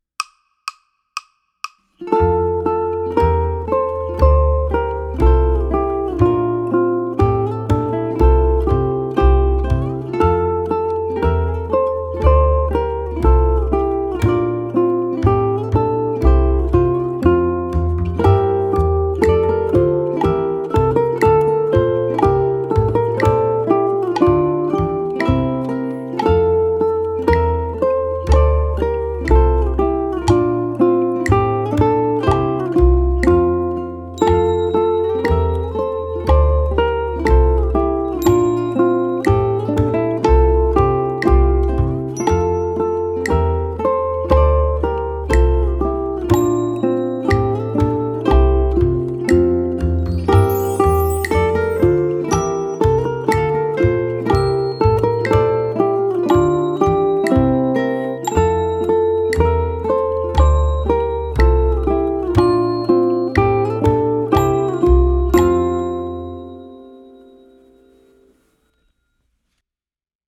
Ode to Joy for ʻUkulele Ensemble
ʻUkulele 2 harmonizes this melody, rendering a choral-like texture when ʻukulele 1 and 2 play together.
ʻukulele
Ode_to_Joy_(revised_Uke_Mix).mp3